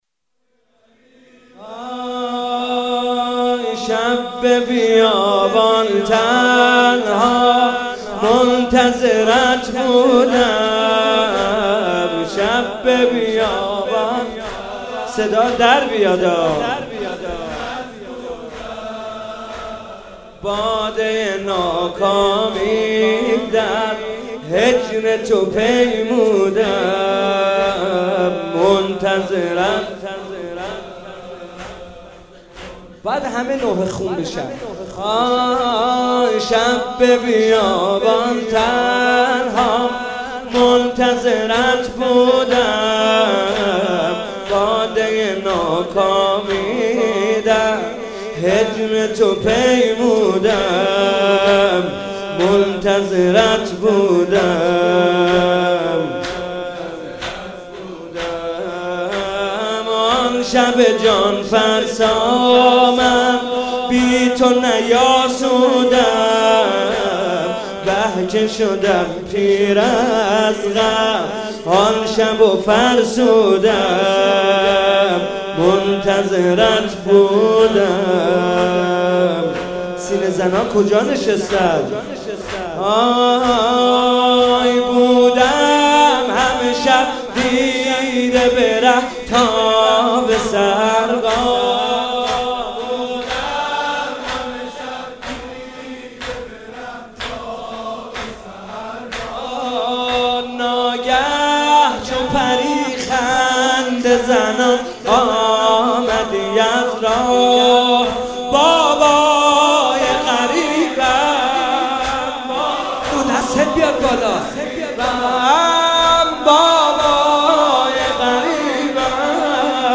هئیت مکتب الزهرا(س)/مراسم شب های ماه مبارک رمضان
شب سوم ماه مبارک رمضان